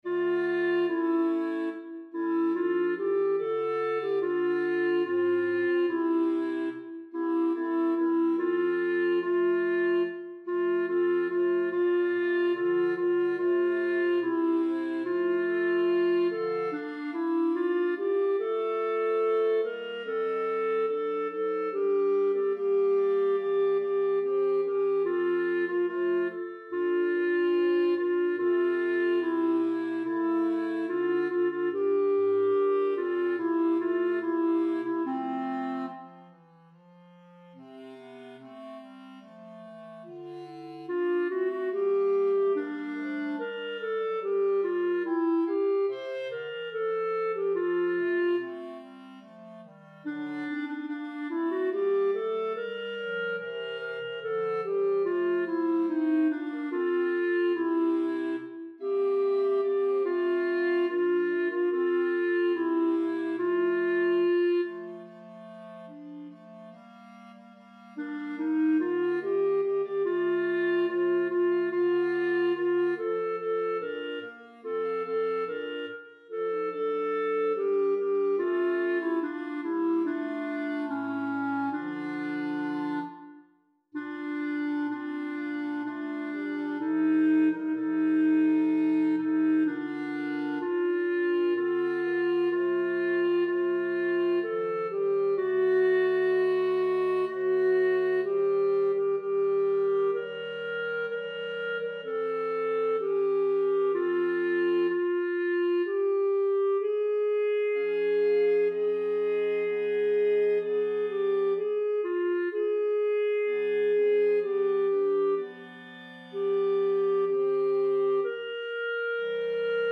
3_Credo_op_83_RR_Alto.mp3